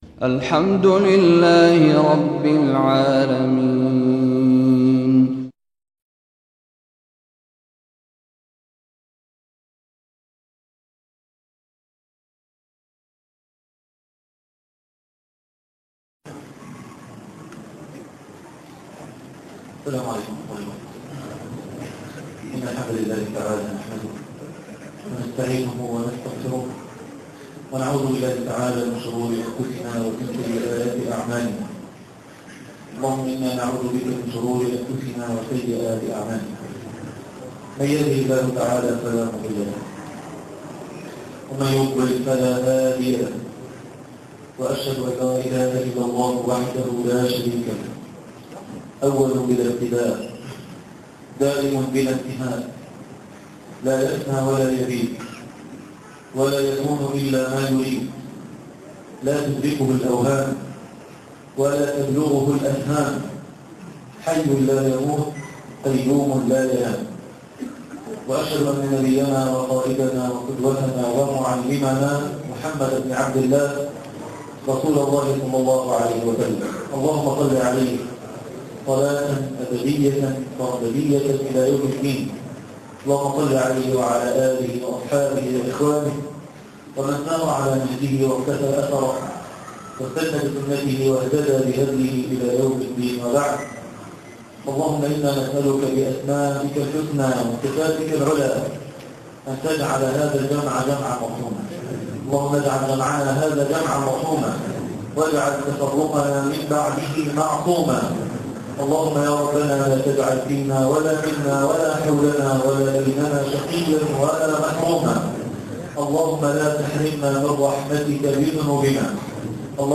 إنه ربي - الدرس الحادي عشر